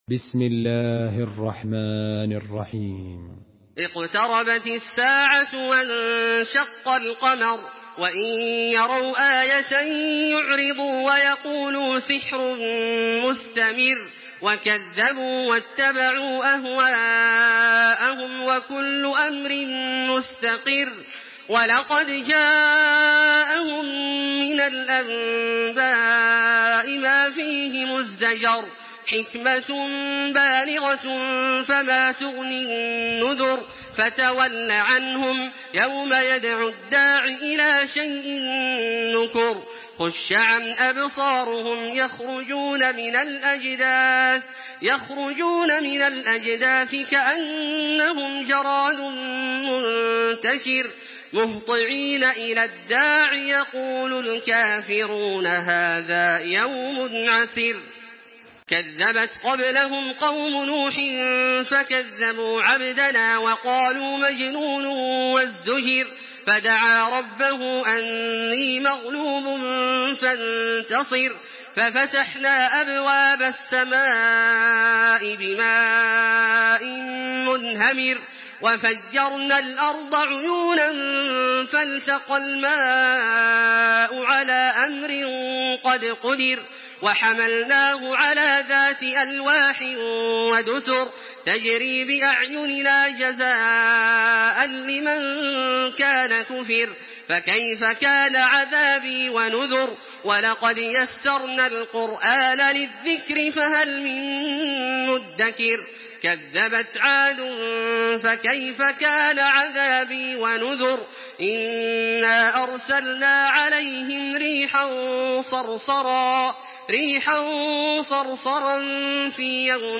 تراويح الحرم المكي 1426
مرتل